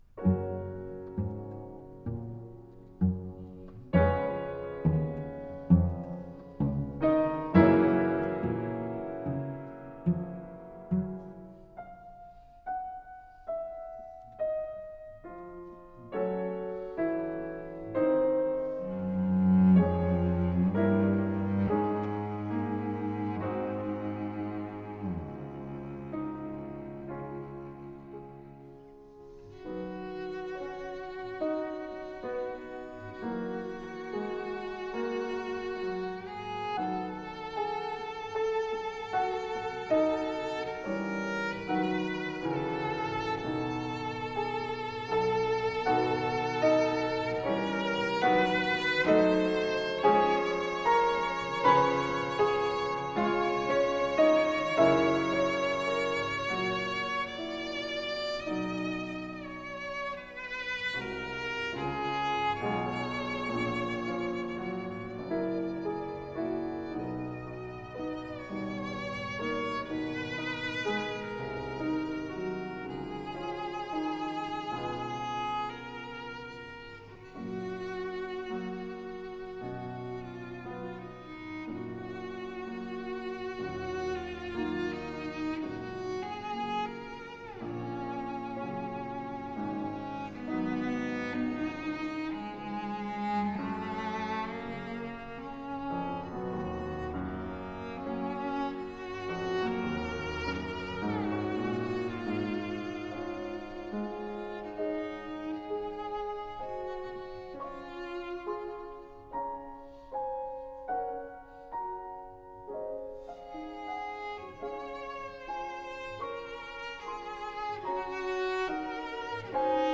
Cello sonata